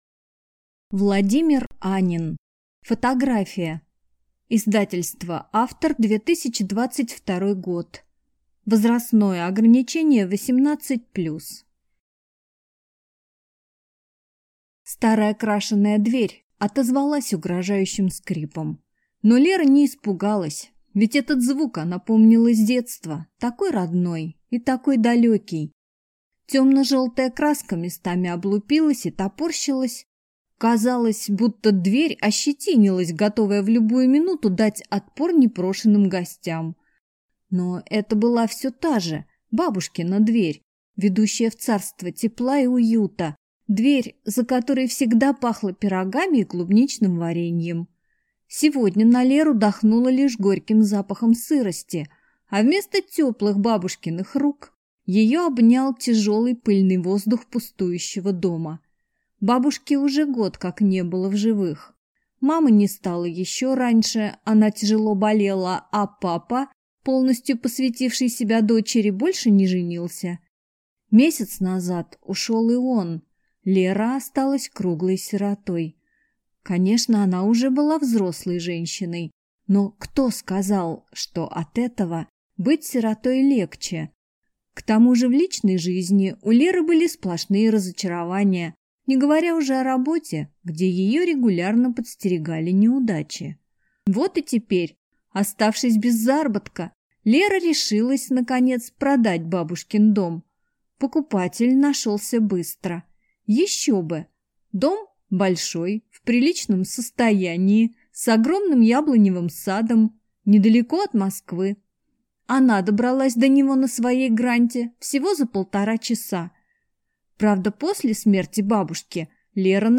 Аудиокнига Фотография | Библиотека аудиокниг